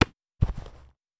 Foley Sports / Baseball - Cricket / Glove Catch Diving.wav
Glove Catch Diving.wav